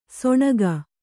♪ soṇaga